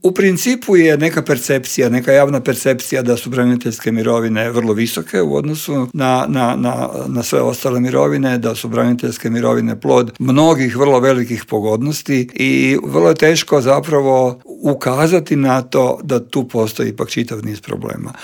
Saborski zastupnik iz redova platforme Možemo! Damir Bakić u Intervjuu Media servisa poručio je da će se povećanje cijena goriva preliti i na druga poskupljenja: "Teret ove krize podnijet će građani i umirovljenici".